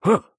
*跳跃时的声音*